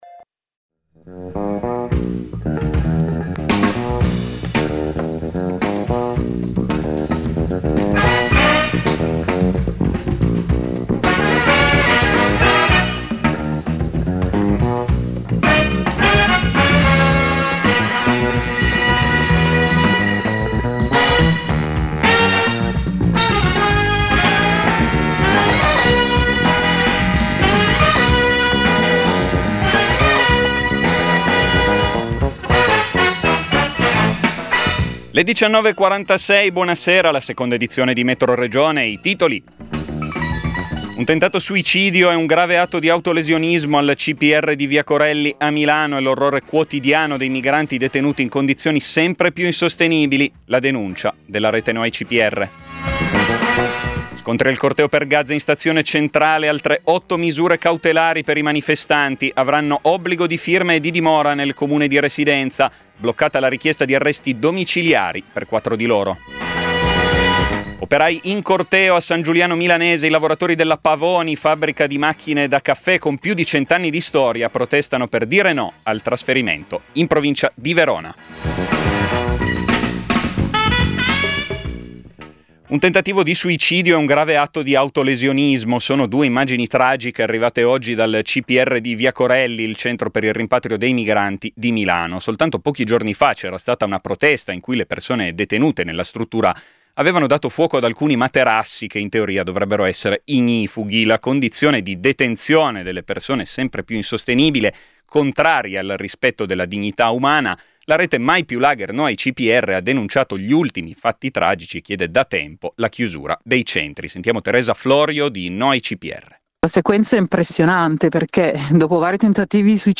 Metroregione è il notiziario regionale di Radio Popolare.